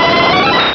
Televersement cris 4G.
Cri_0135_DP.ogg